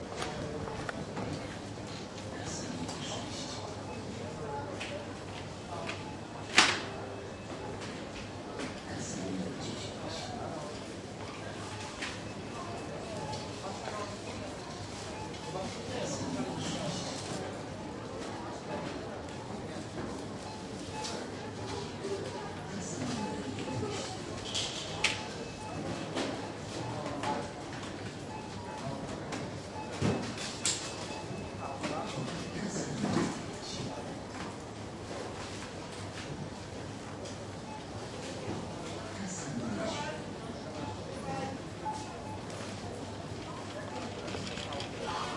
描述：这是波兰家乐福拥挤的超市的声音（波兰华沙Złote Tarasy）。 有换气扇的声音，人们的脚步声，收银机的嘟嘟声，购物篮，统一排队的自动导演说话"kasa numer 6, kasa numer 10"等等
标签： 抛光 环境 永世 超市 波兰 电影 人群 地点 家乐福
声道立体声